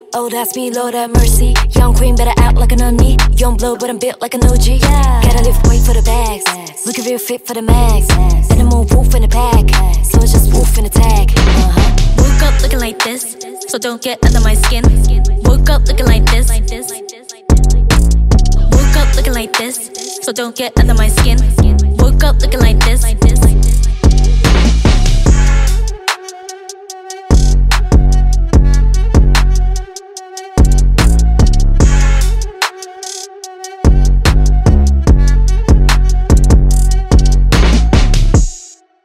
Categoria Rap/Hip Hop